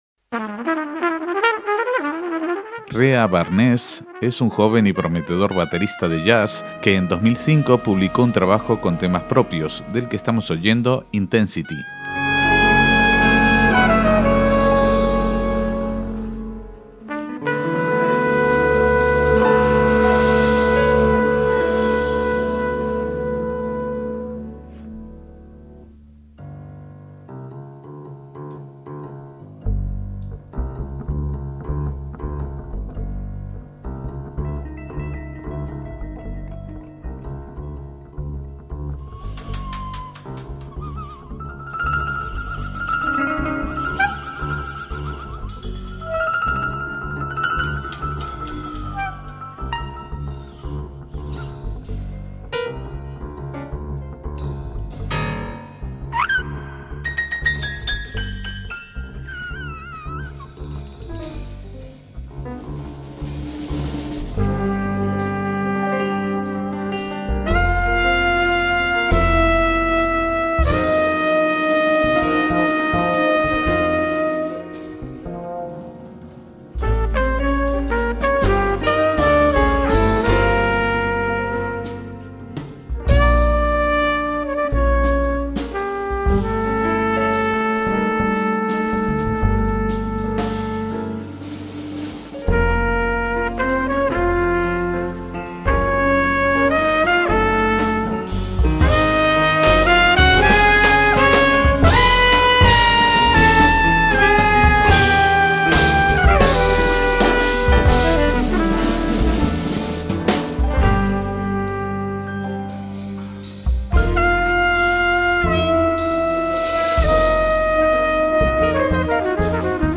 baterista de jazz israelí
guitarra eléctrica y oud
trompeta
saxo tenor